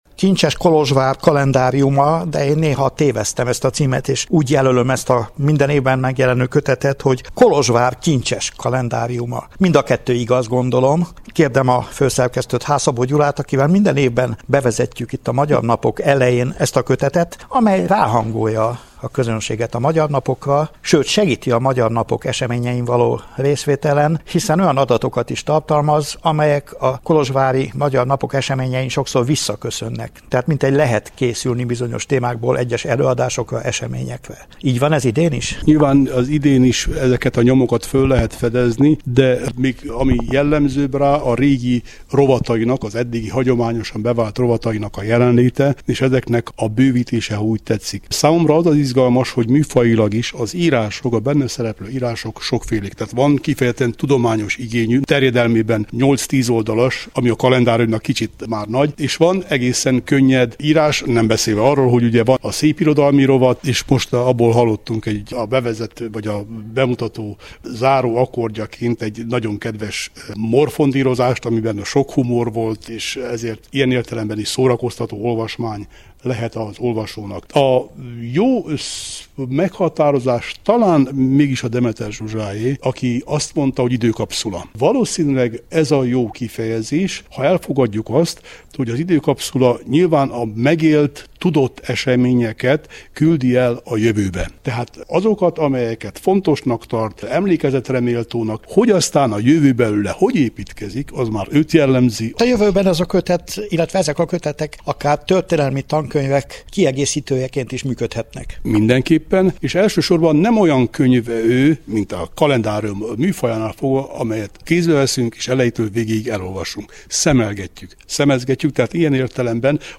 Az új kalendárium bemutatójára a Bánffy Miklós Operastúdió rendezvénytermében került sor a szerzők jelenlétében.